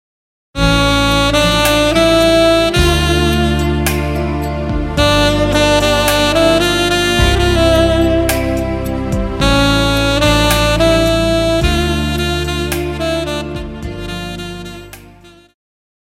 Jazz
Band
Instrumental
Smooth Jazz
Only backing